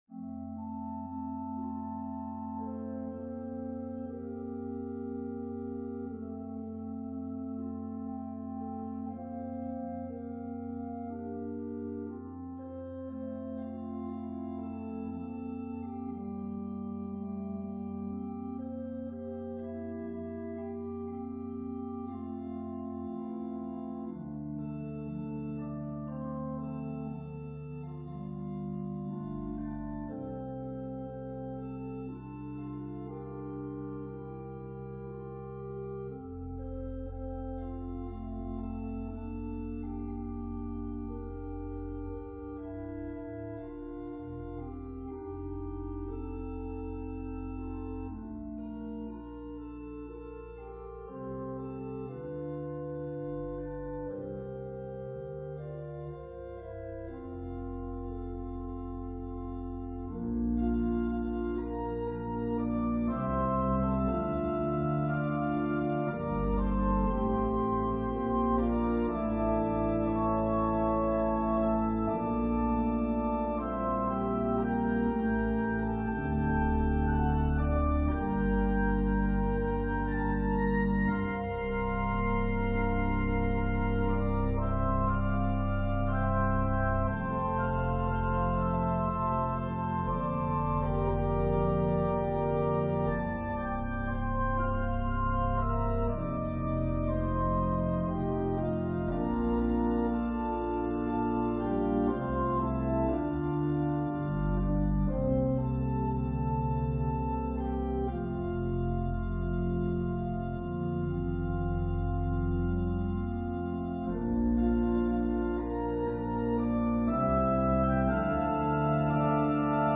This is an organ solo arrangement (not the accompaniment).